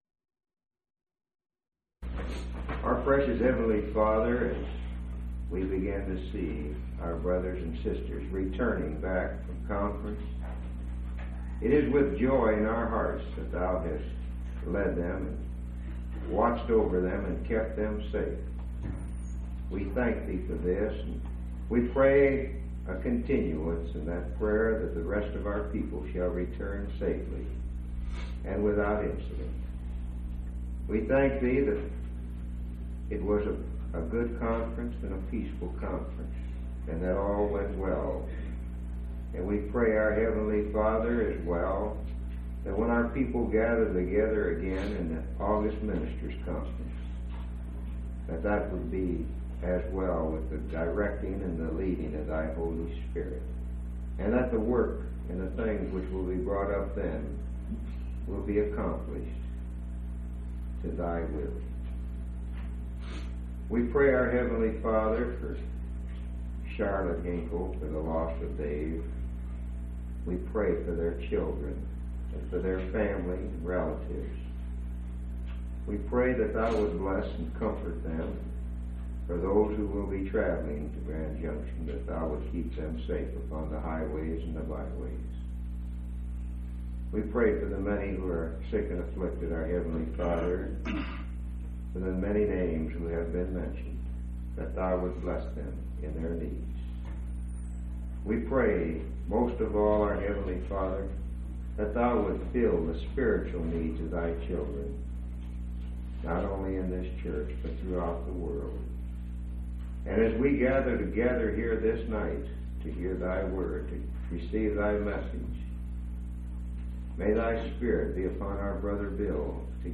4/13/1986 Location: Phoenix Local Event